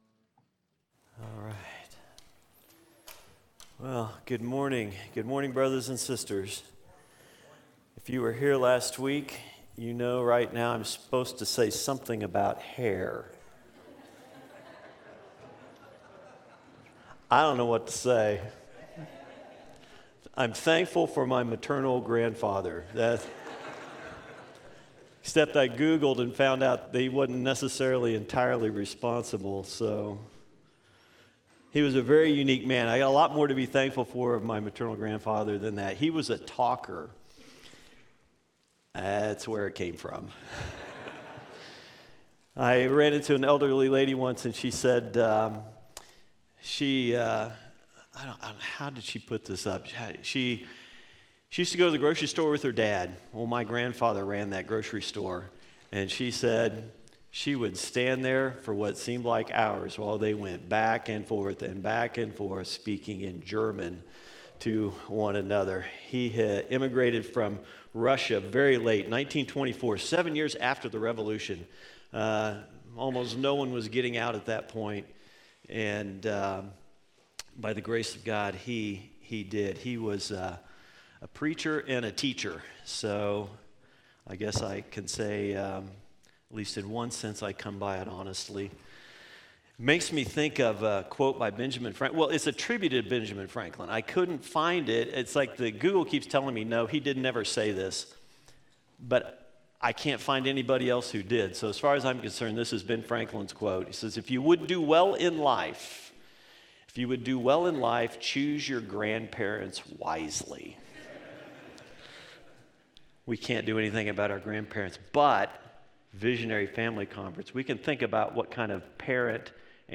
Sermons – Wichita Bible Church